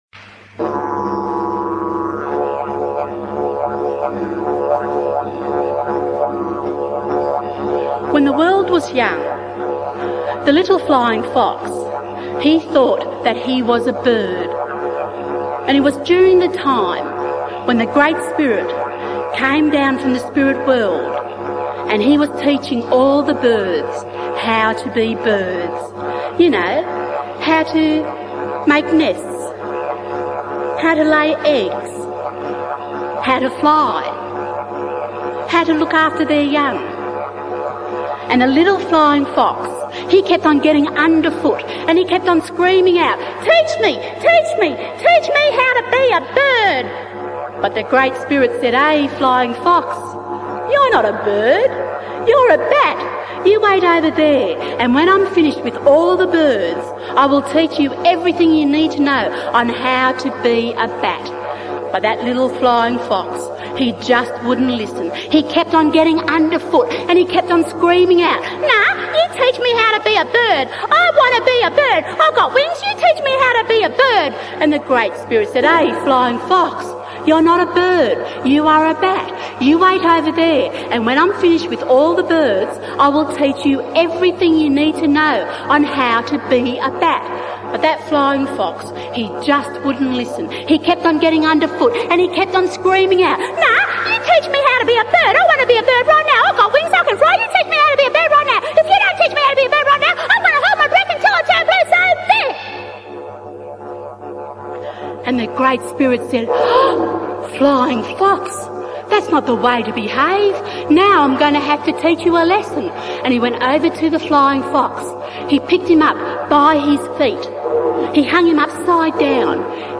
Dreamtime storytelling